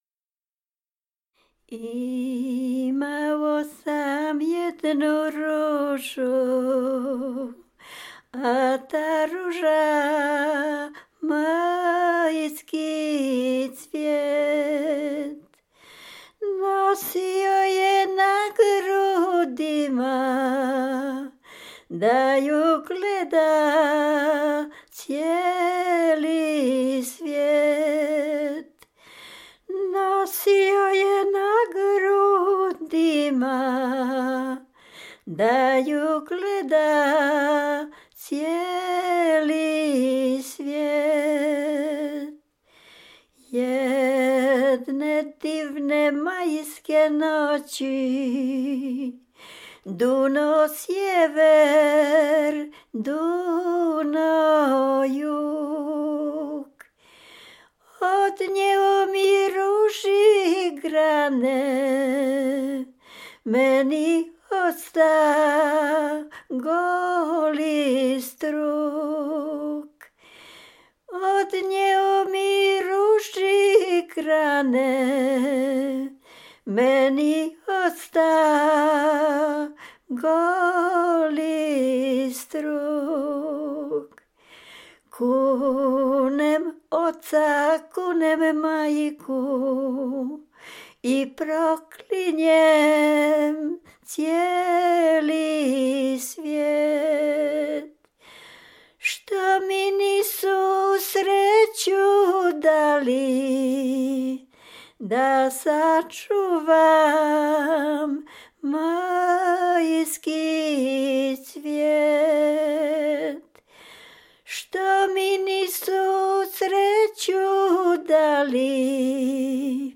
Dolny Śląsk, powiat bolesławiecki, gmina Osiecznica, wieś Przejęsław
Sevdalinka